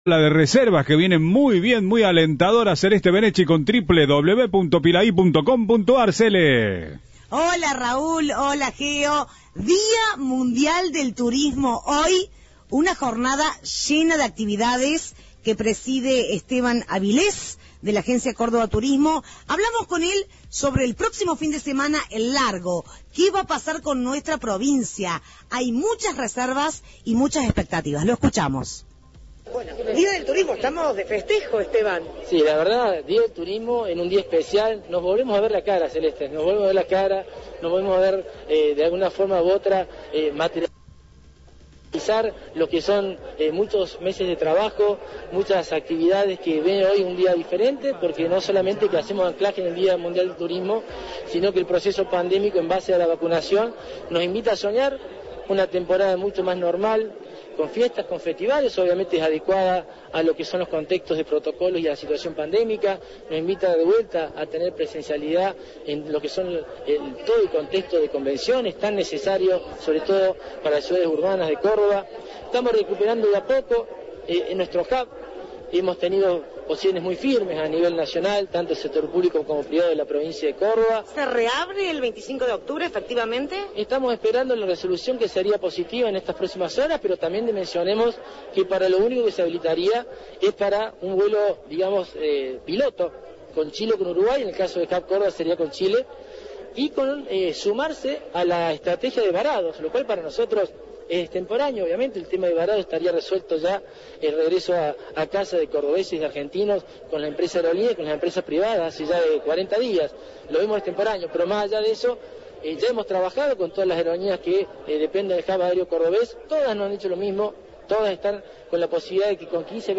"Estamos muy contentos con el programa PreViaje, superó nuestras expectativas ampliamente", reafirmó Lammens esta mañana en declaraciones formuladas a Radio 10, en el marco del Día Mundial del Turismo.